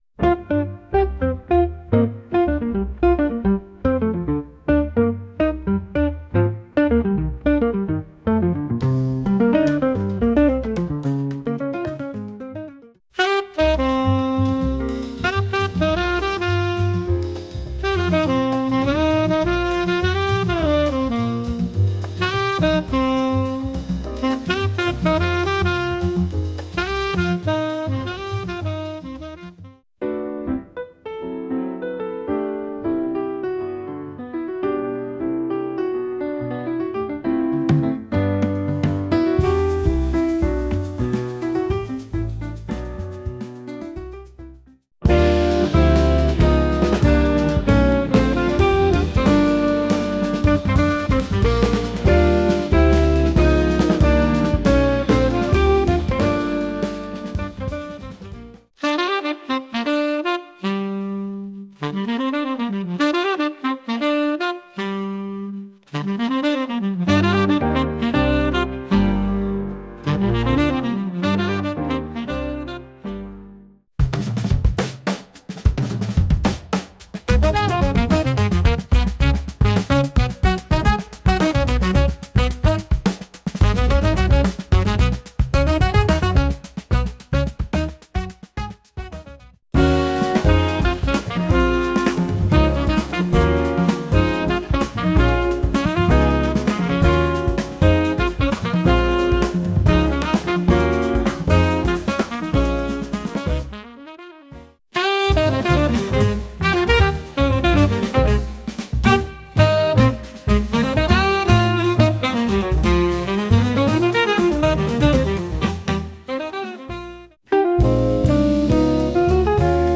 groove to the jungle beats
Enhance your projects with the soothing sounds